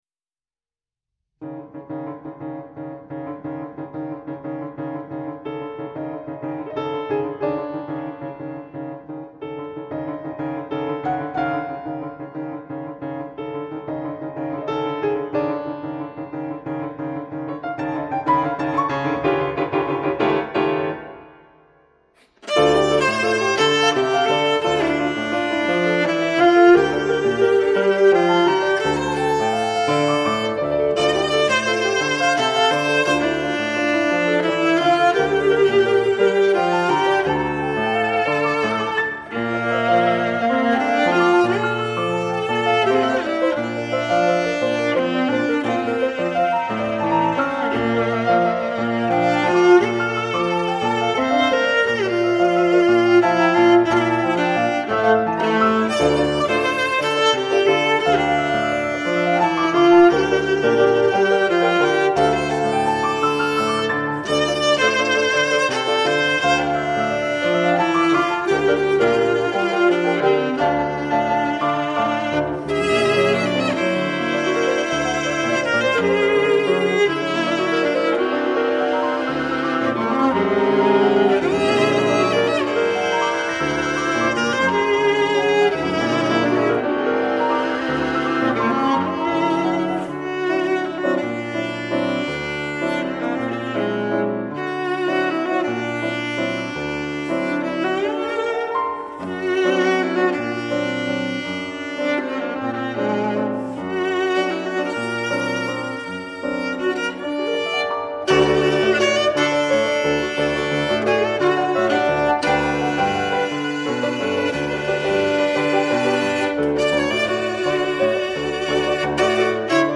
The mood for this movement is energetic and full of life.
Viola + Piano